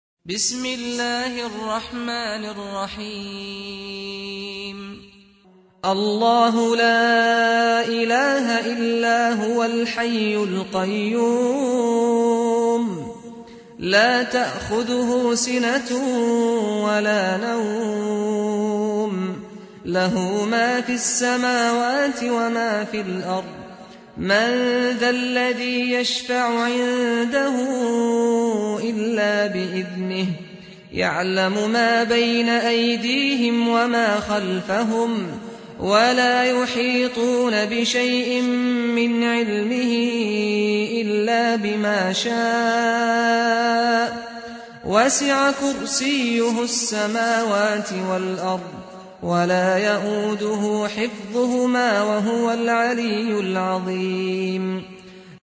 002-Ayat_Kursiy_(Verset_du_Trone)_Cheikh_Saad_Al_Ghamdi.mp3